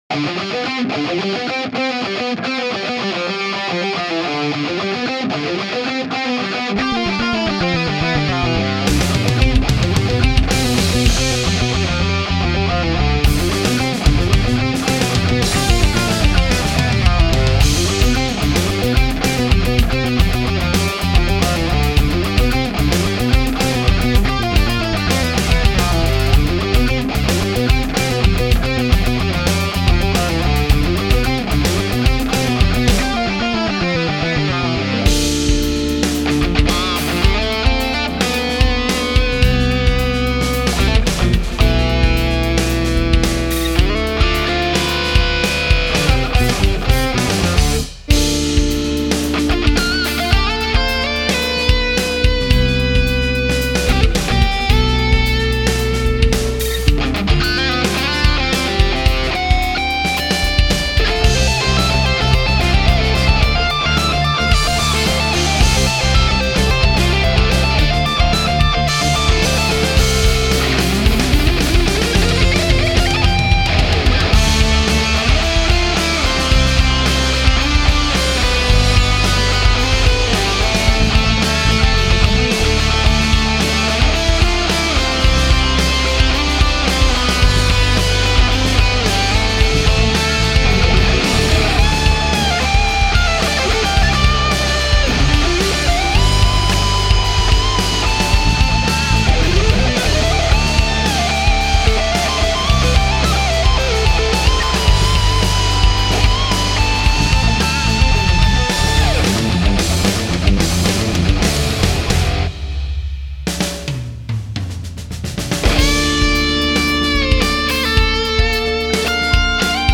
Rock, Metal